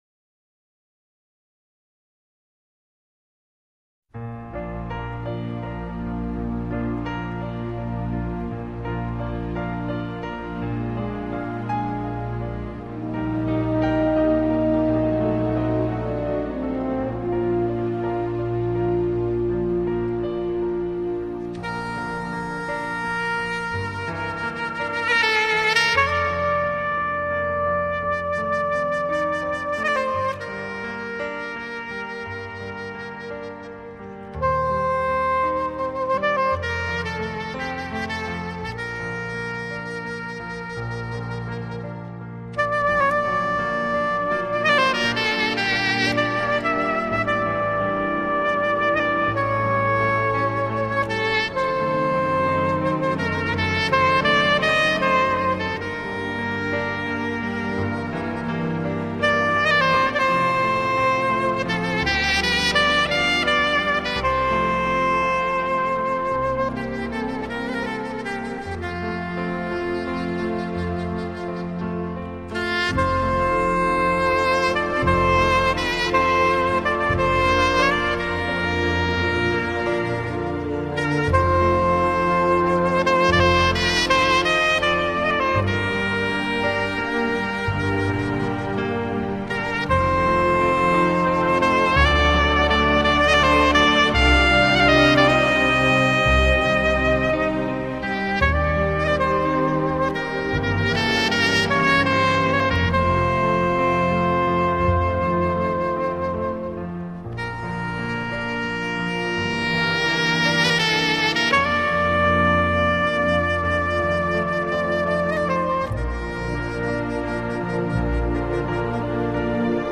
Звучит музыка " Аве Мария", учитель читает слова.